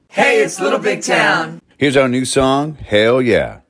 Audio / LINER Little Big Town (Hell Yeah) 1